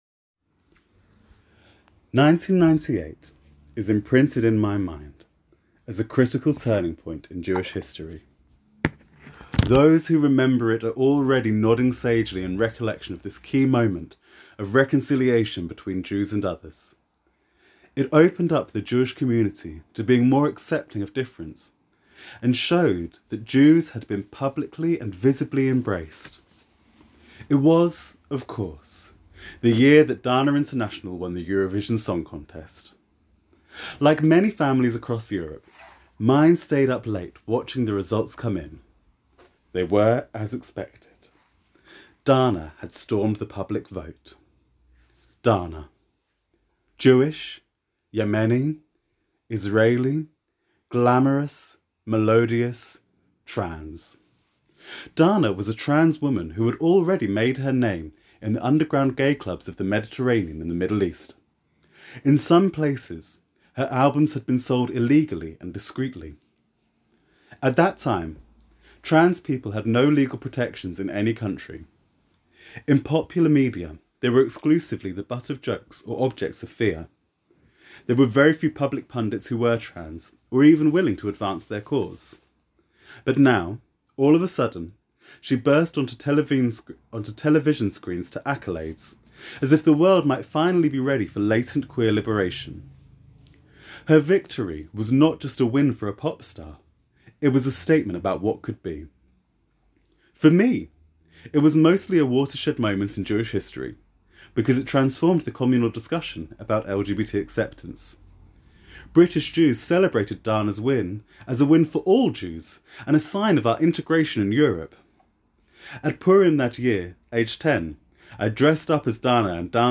sermon · social justice · torah